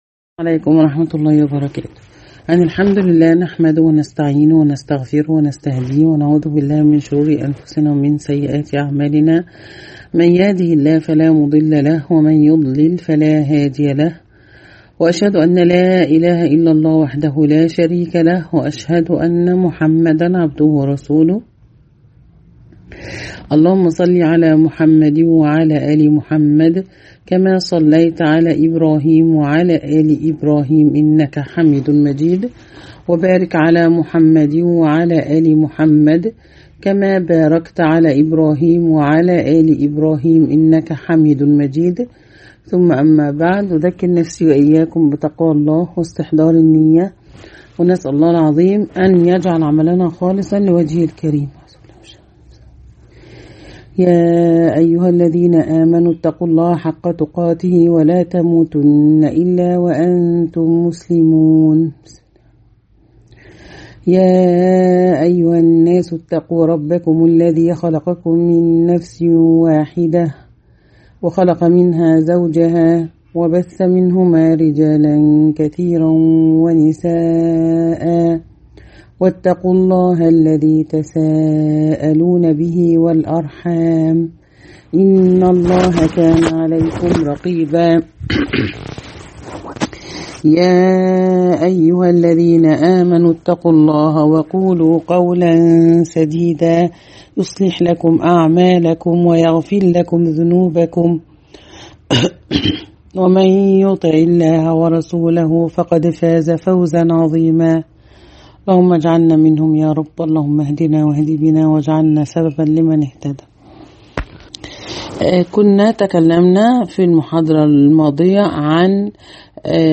النحو_المحاضرة الثامنة